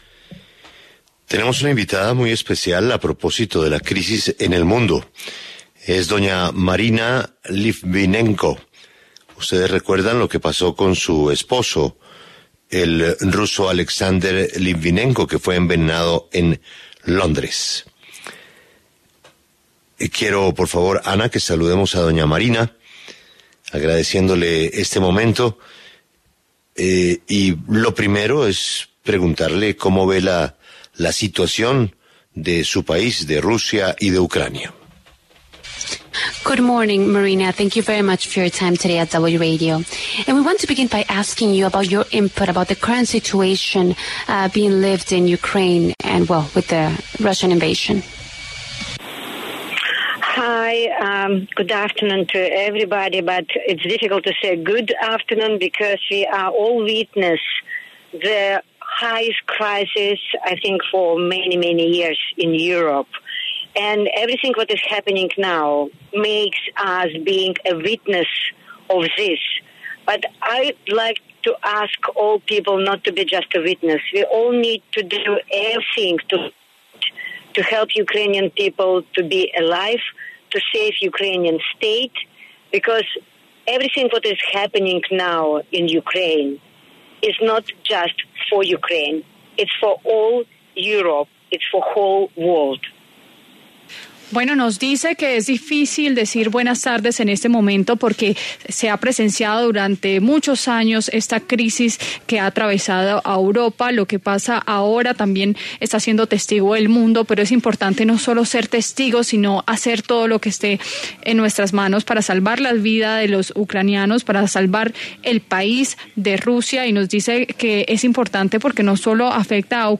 En el encabezado escuche la entrevista completa con Marina Litvinenko, la viuda del espía ruso Alexander Litvinenko que falleció envenenado con polonio radioactivo en 2006.